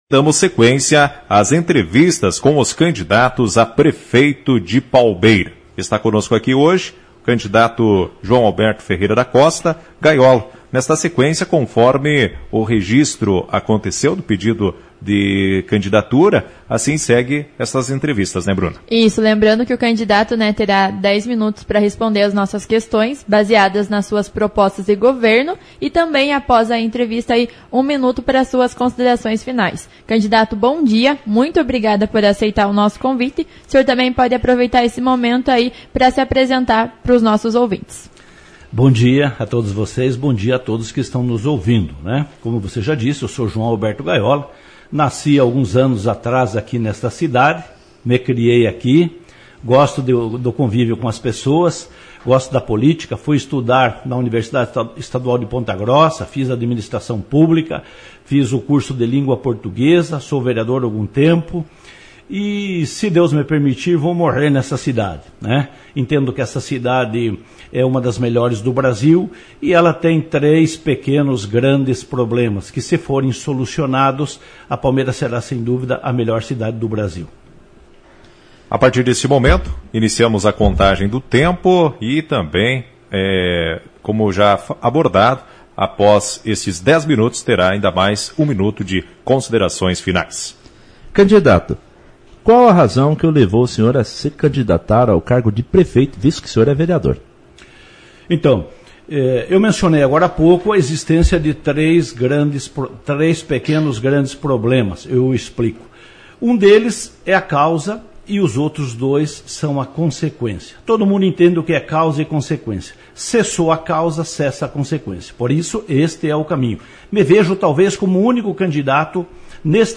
As perguntas foram elaboradas através do Plano de Governo do Prefeiturável. Ouça o áudio da entrevista completa: